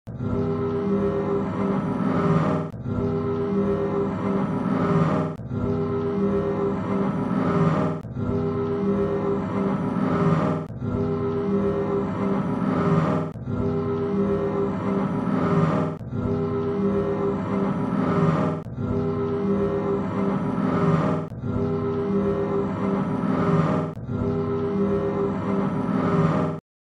Fan Made Ghidorah Roar Sound Effects Free Download